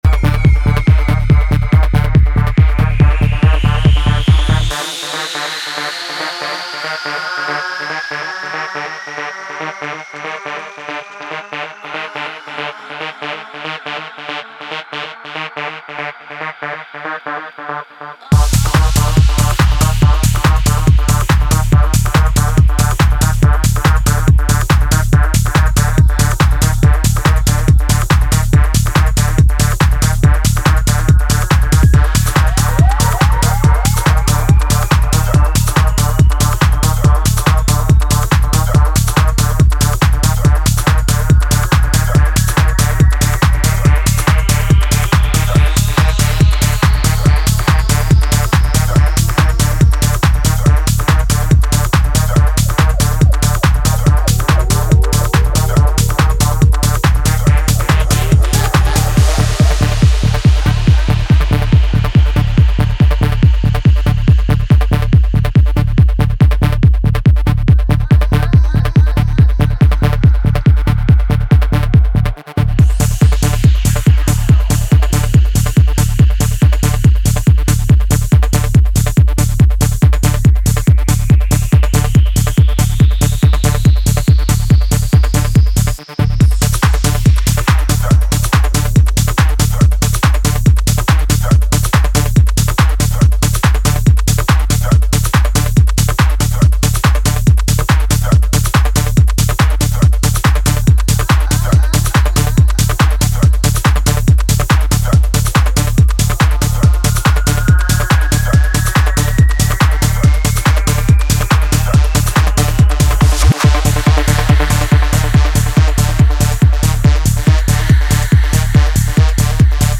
club ready bangers
Tech House , Techno , Trance